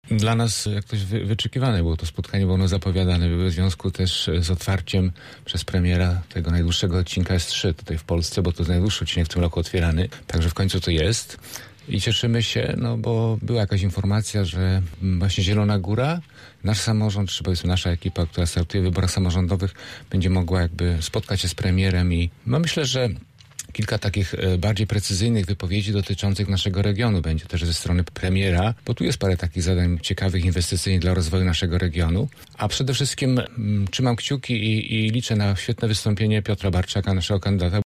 Myślę, że premier nas wzmocni – mówił w Rozmowie Punkt 9 Marek Budniak, radny klubu PiS: